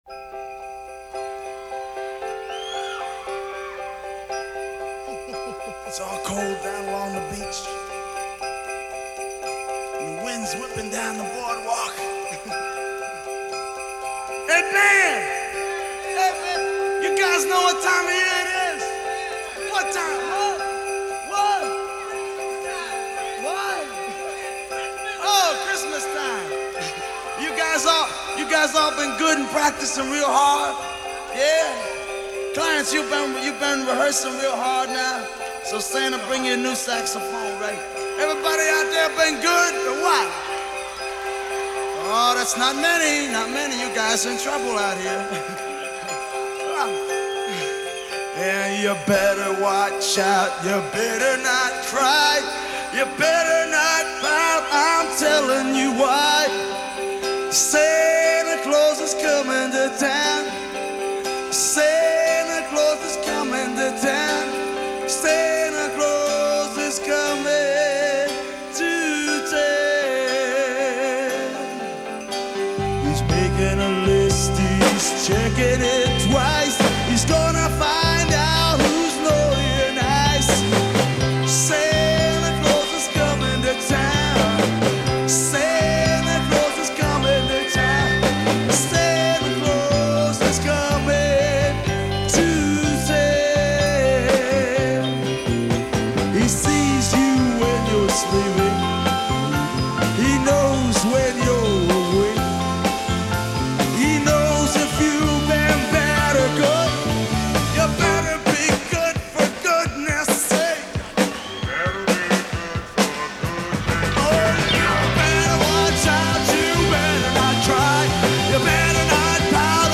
los villancicos más roqueros